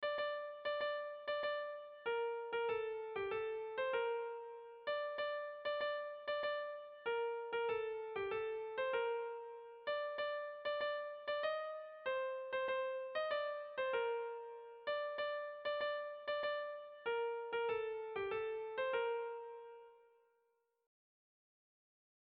Kontakizunezkoa
Zortziko txikia (hg) / Lau puntuko txikia (ip)
AABA